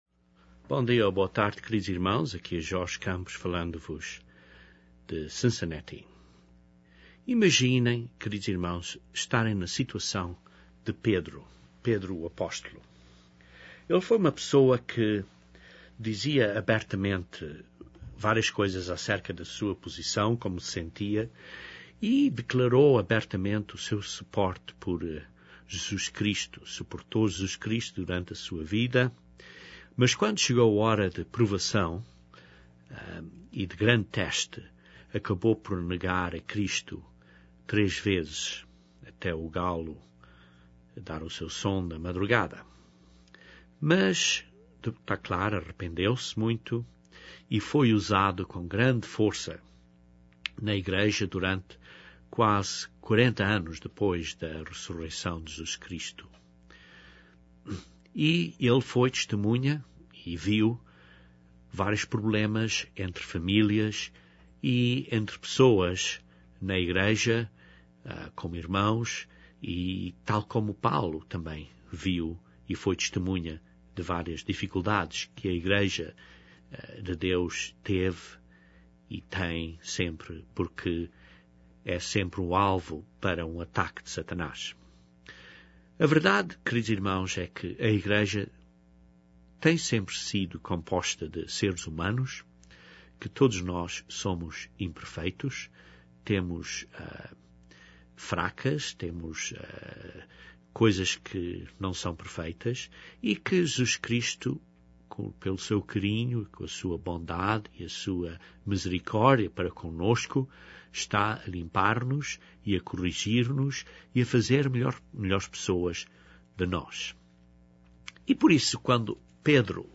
Este sermão cobre vários exemplos da vida de Jesus Cristo nos quais ele demonstrou graça para com várias pessoas, incluindo pecadores.